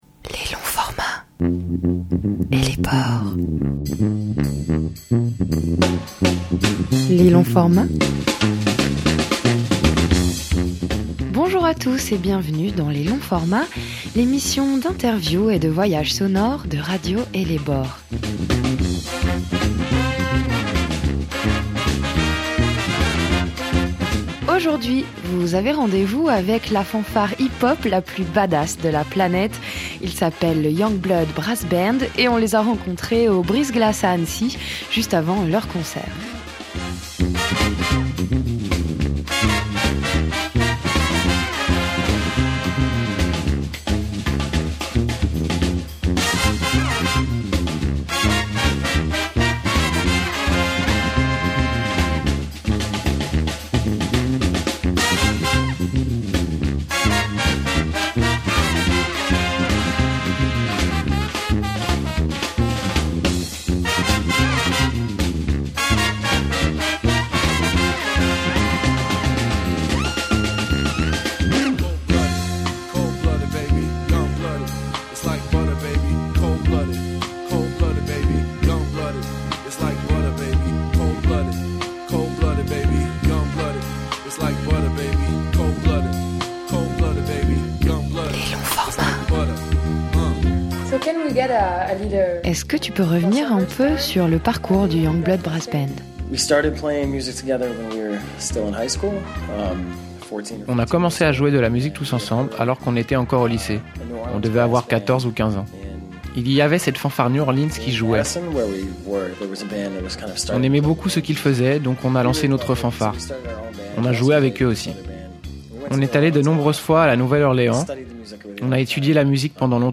The Youngblood Brass Band : l'interview - Radio Ellebore
Nous nous sommes rencontrés juste avant leur concert au Brise-Glace à Annecy, l’occasion de revenir sur le parcours du Youngblood Brass Band, les racines de la fanfare new-orleans, leur avis sur l’état du monde et ce qu’il y a de punk en eux.